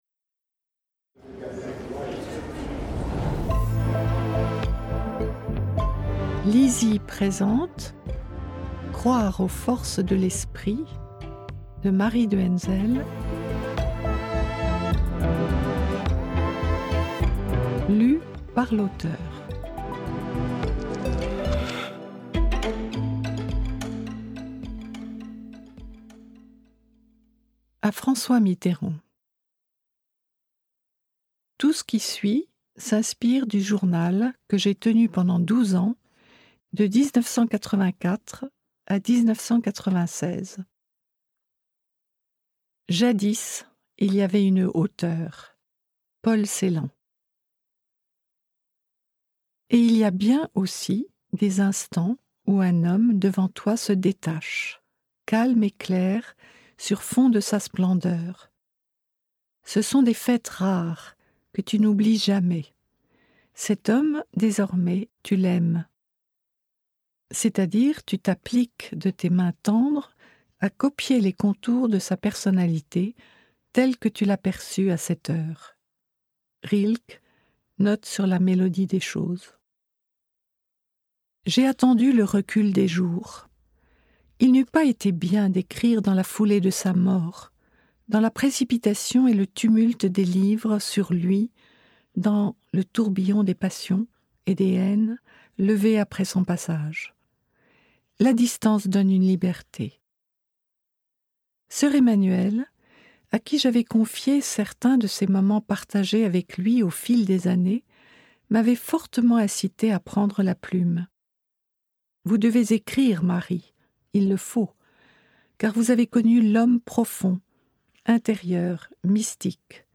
Extrait gratuit - Croire aux forces de l'esprit de Marie de Hennezel